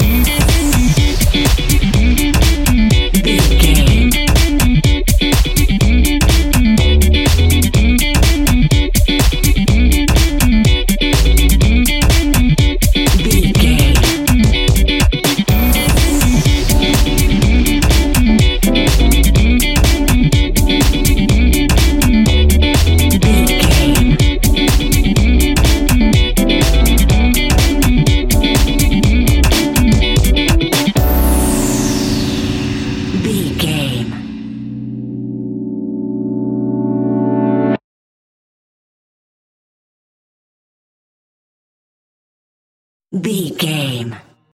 Aeolian/Minor
groovy
uplifting
driving
energetic
repetitive
bass guitar
synthesiser
electric guitar
drums
piano
deep house
nu disco
upbeat
funky guitar
clavinet
fender rhodes
synth bass
horns